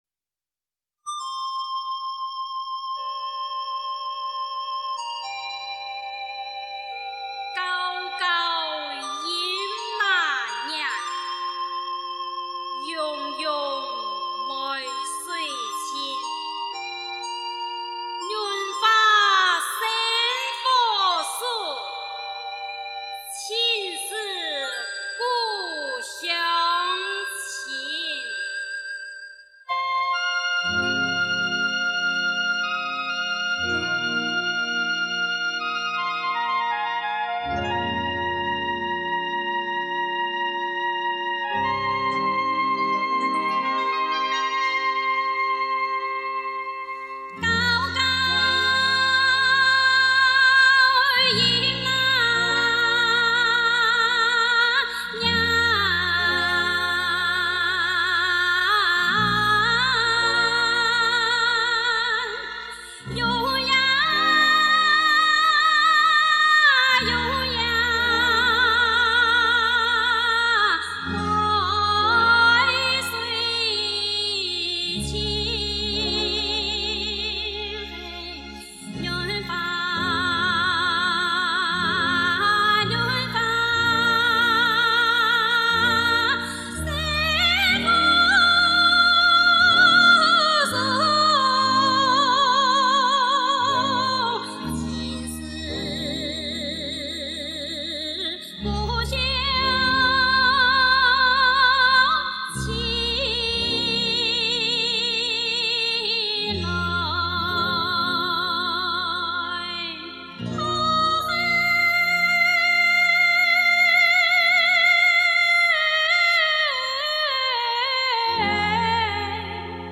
用客家语演唱